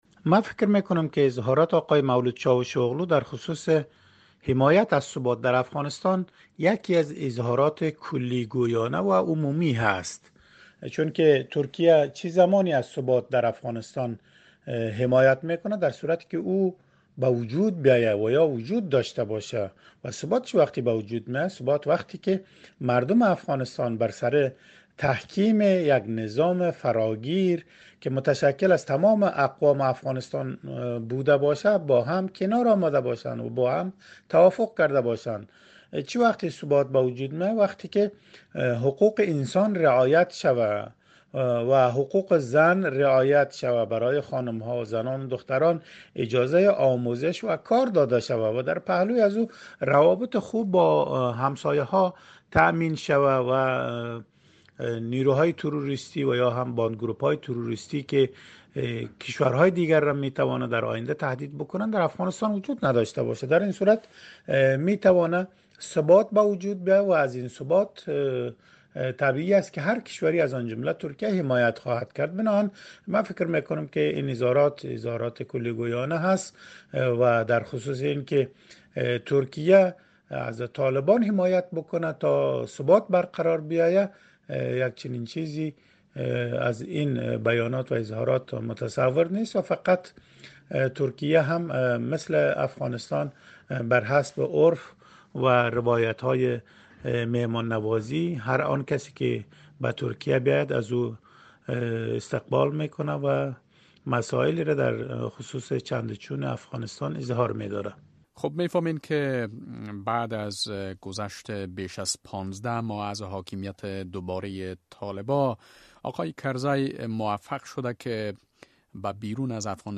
مصاحبه - صدا